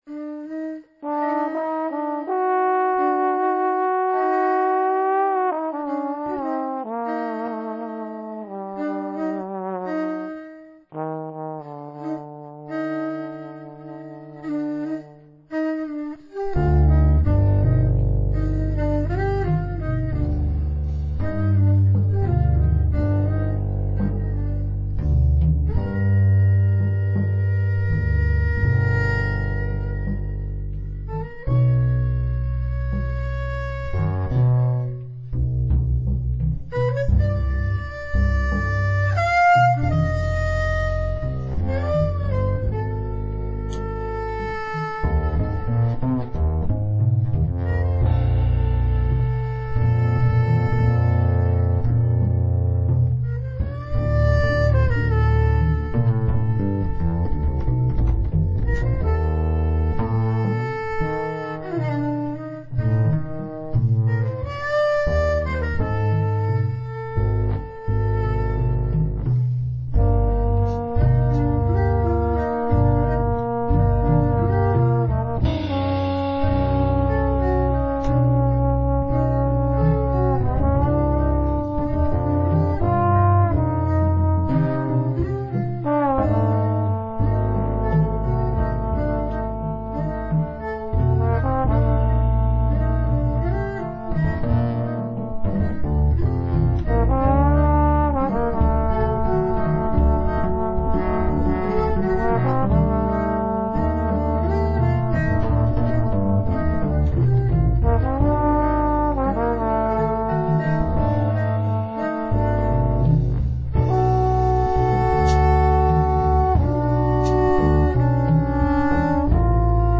violin, percussions, vocal
trombone
doublebass
drums
at The Mascherona Studio, Genova - Italy